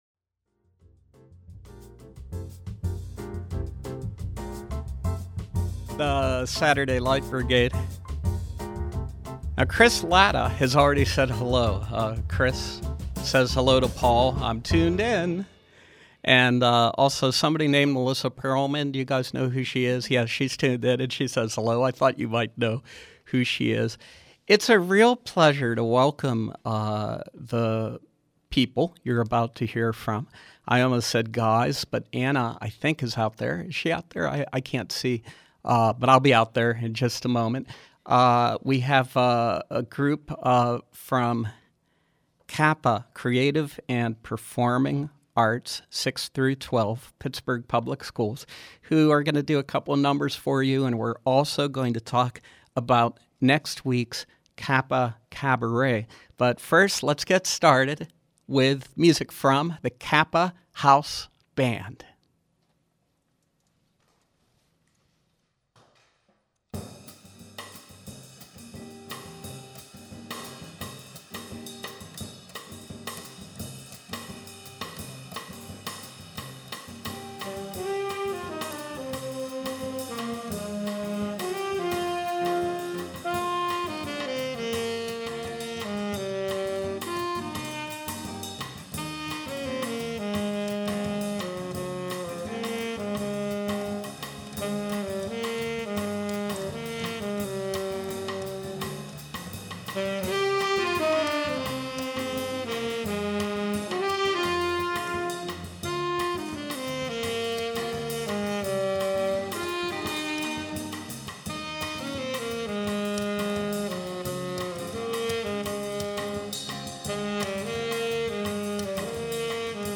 From 5/11/13: The CAPA House Band with jazz in preview of CAPA Caberet, a 5/18 benefit for Pittsburgh Creative and Performing Arts 6-12